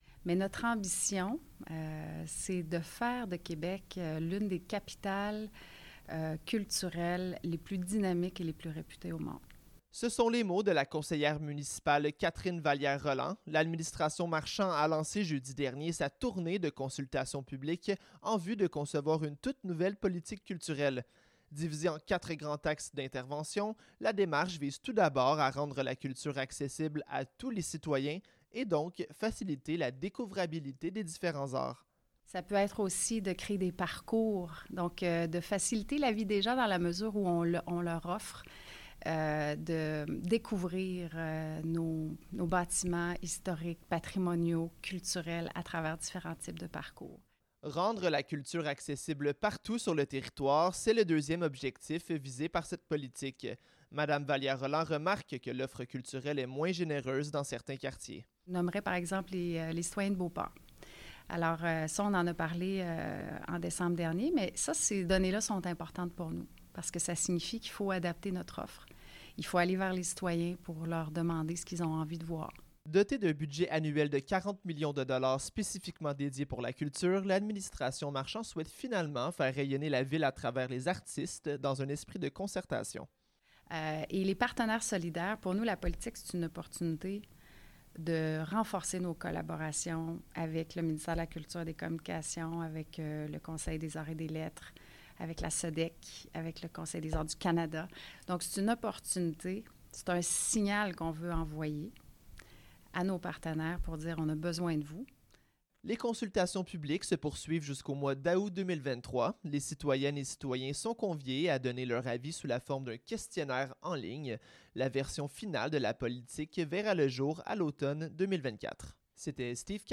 Reportage sur les ondes de CKIA FM :